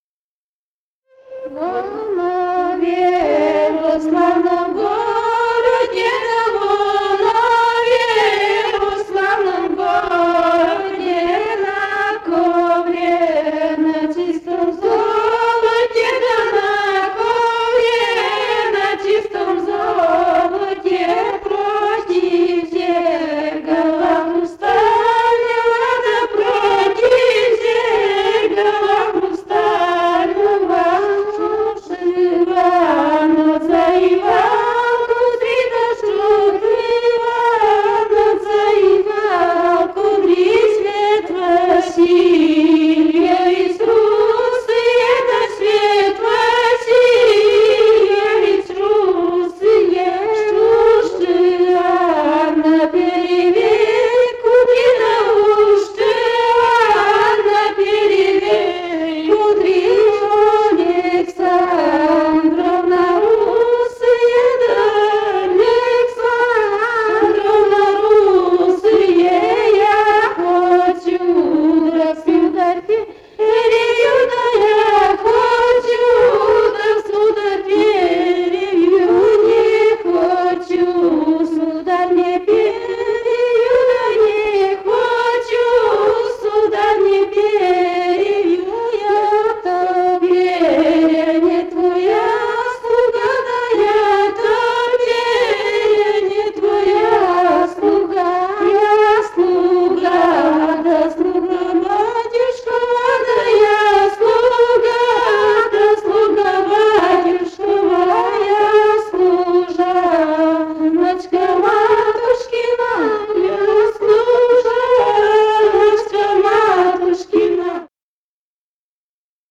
Во Нове, во славном городе» (свадебная).